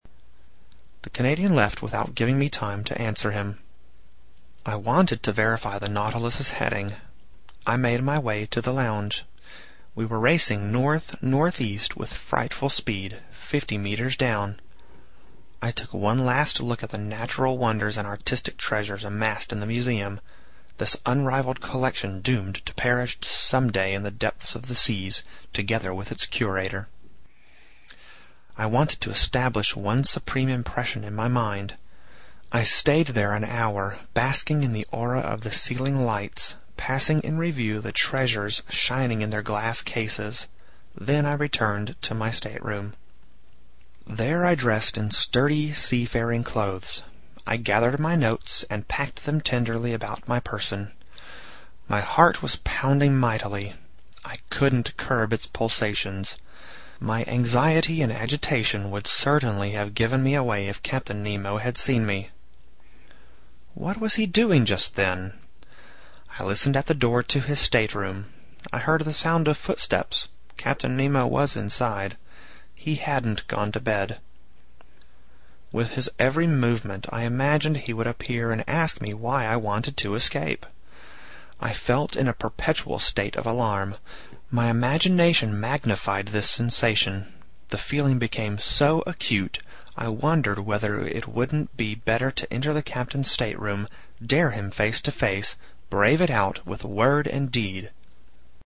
在线英语听力室英语听书《海底两万里》第559期 第35章 尼摩船长的最后几句话(6)的听力文件下载,《海底两万里》中英双语有声读物附MP3下载